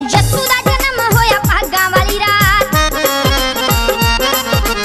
yasu da janam Meme Sound Effect
This sound is perfect for adding humor, surprise, or dramatic timing to your content.